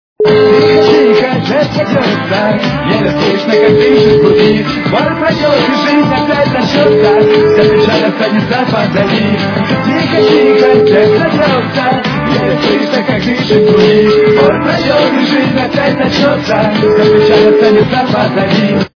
русская эстрада